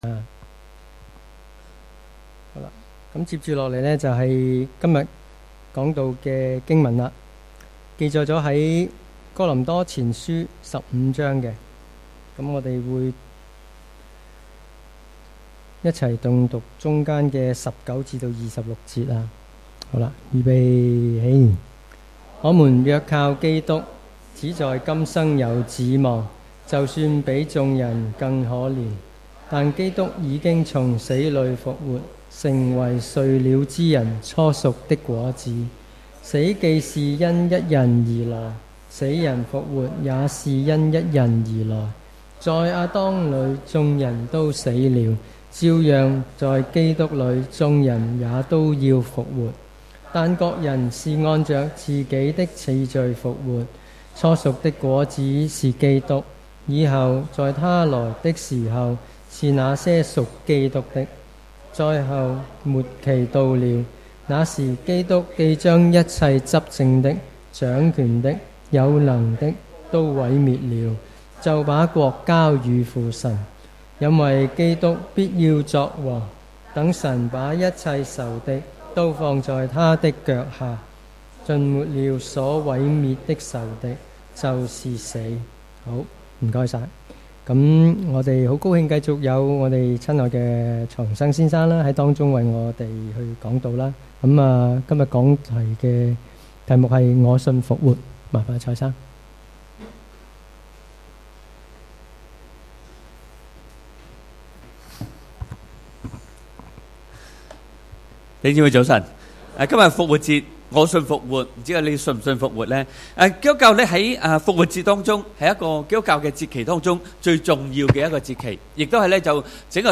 主日崇拜講道 – 我信復活